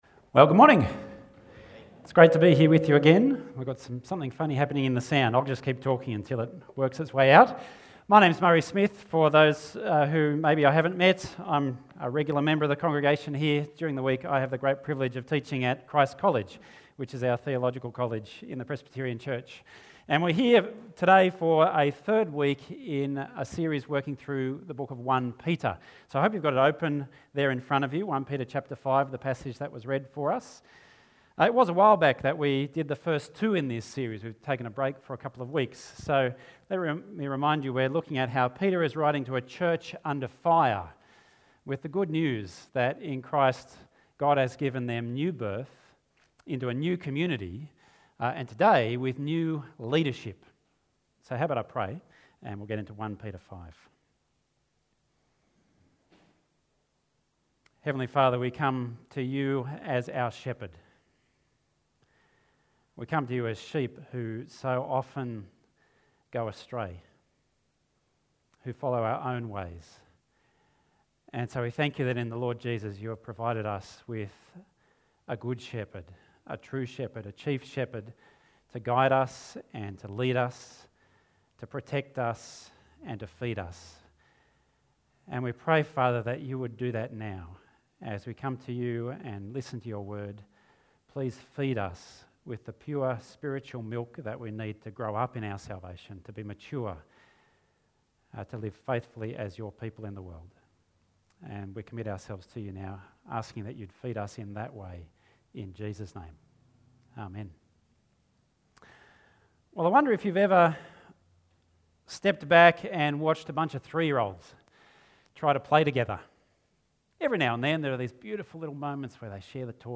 The Church Under Fire Passage: 1 Peter 5:1-14, Psalm 23:1-6 Service Type: Sunday Morning « Camp 2016 Session 2